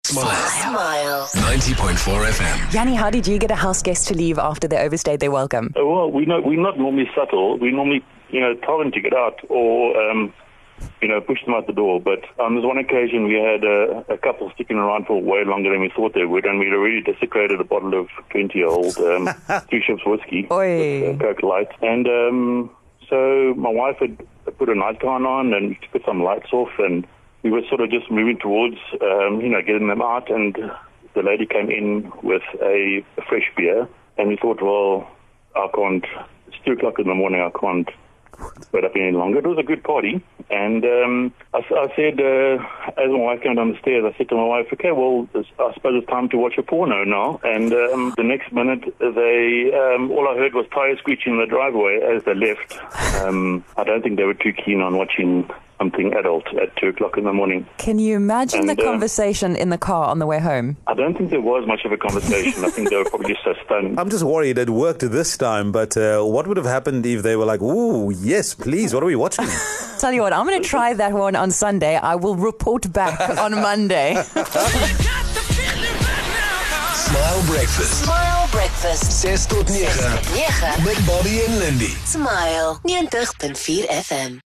In a conversation about hinting to guests that it's time to leave, one caller told us about a moment he wasn't so subtle and seemed to take care of the problem completely.